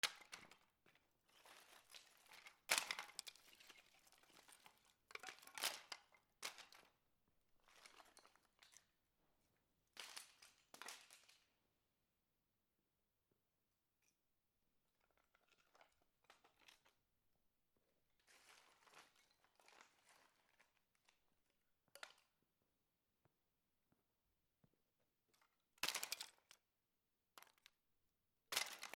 木をごそごそ レベル低
/ M｜他分類 / L01 ｜小道具 /
『チャ』